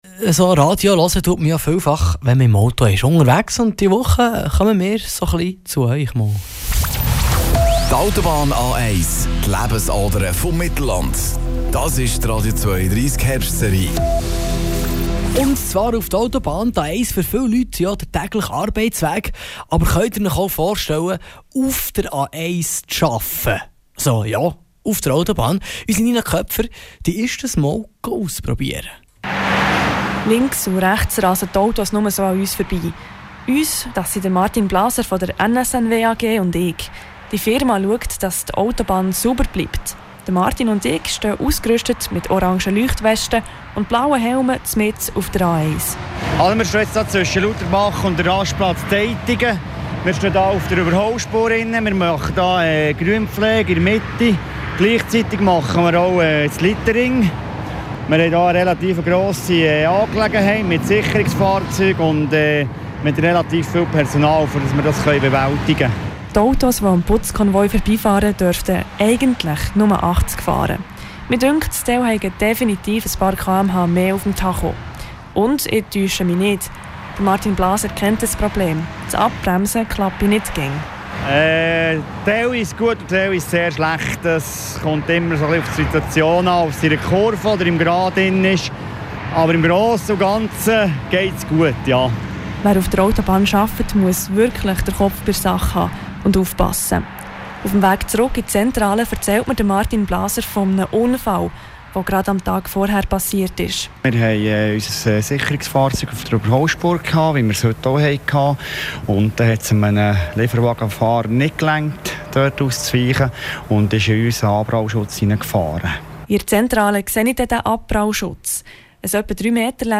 Mit den Mitarbeiter der NSNW AG, die täglich den Abfall wegräume, den wir gedankenlos aus dem Fenster werfen: